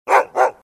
лай собак , звуки животных